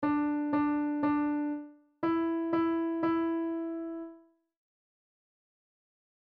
On the piano, play Mary Had A Little Lamb
D D D E E E